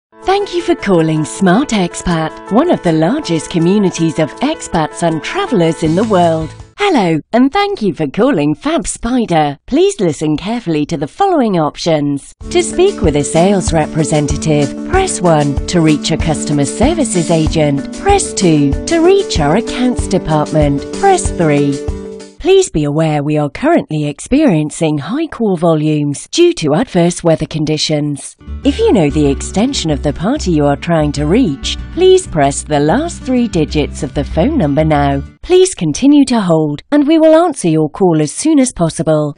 Female Voice 1
Female5.mp3